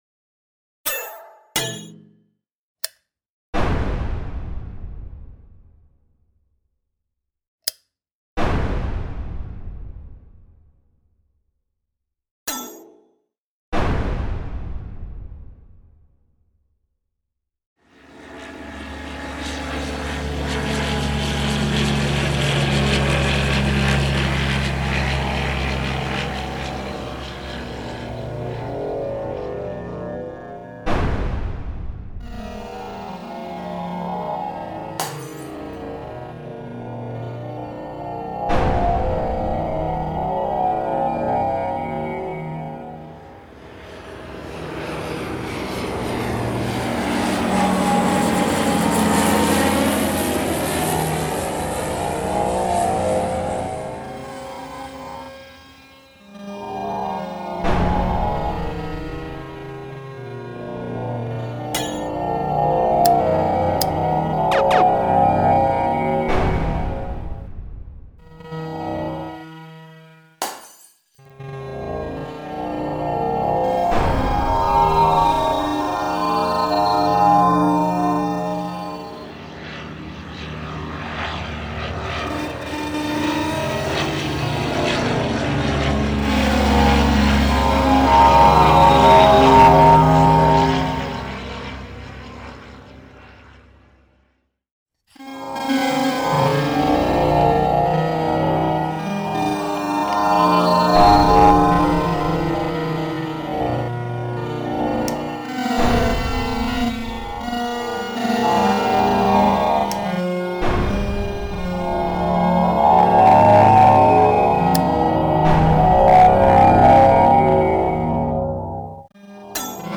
AI generated auditory artwork